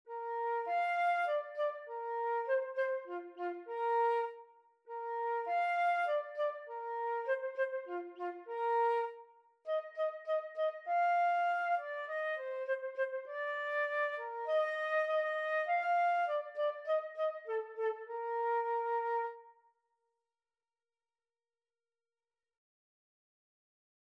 Flute version
Traditional Music of unknown author.
Bb major (Sounding Pitch) (View more Bb major Music for Flute )
4/4 (View more 4/4 Music)
F5-F6
Moderato
World (View more World Flute Music)